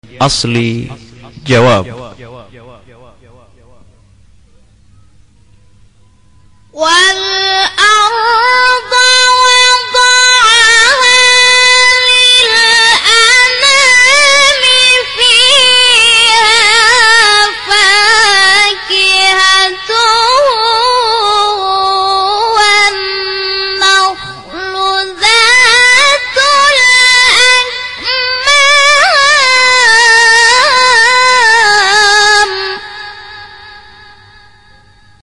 بیات اصلی جواب3.mp3
بیات-اصلی-جواب3.mp3